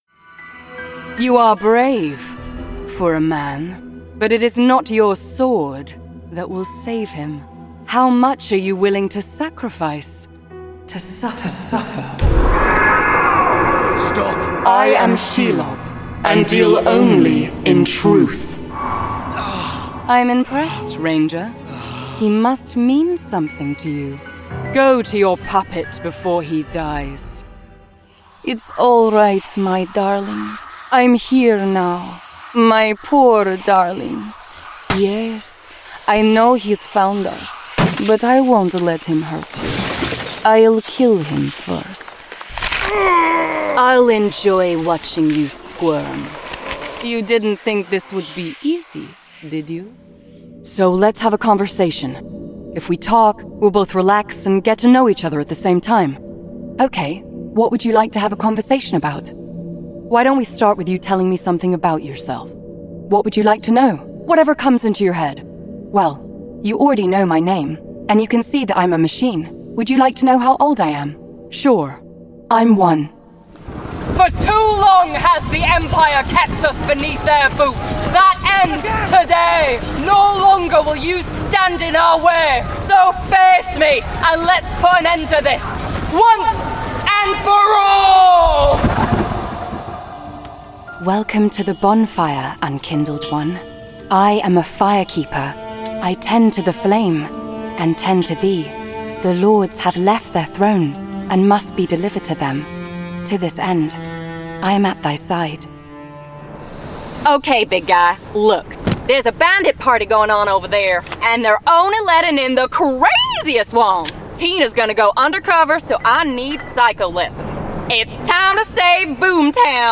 Video Game Reel
• Home Studio
She speaks with a cool, confident authority that would be perfect for commercial and corporate projects.